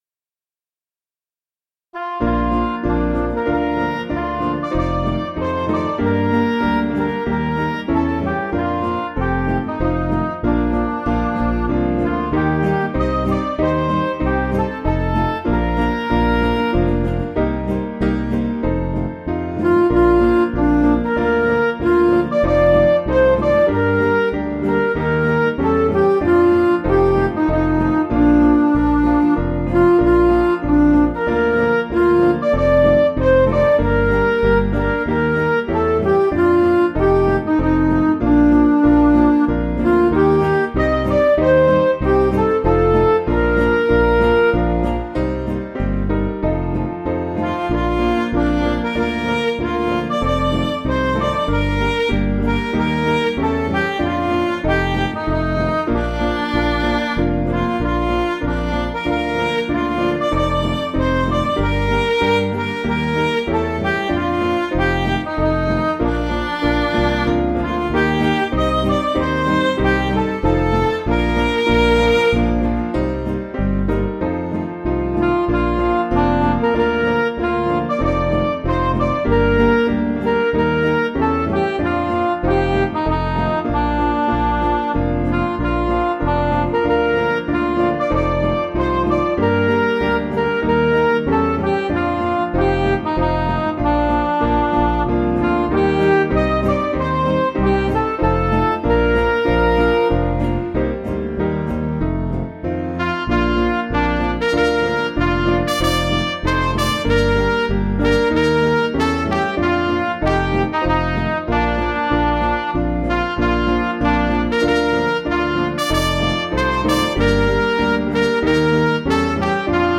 Piano & Instrumental
(CM)   4/Bb
Midi